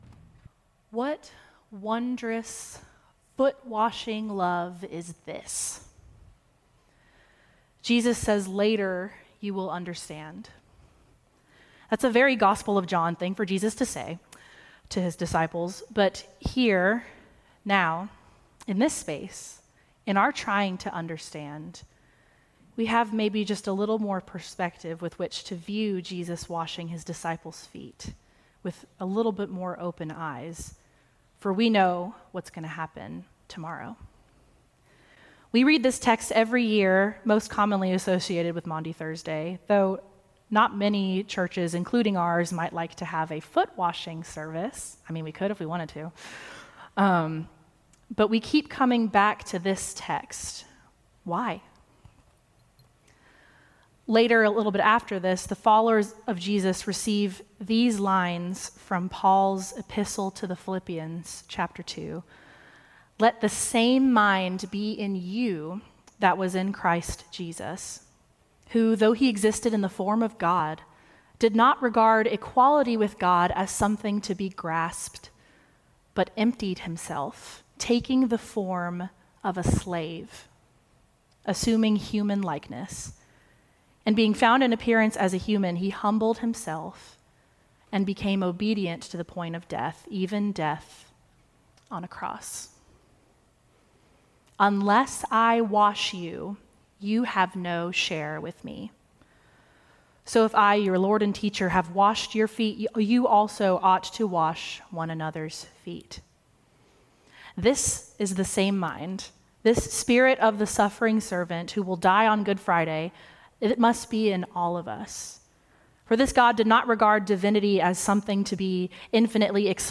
Service Type: Seasonal & Occasional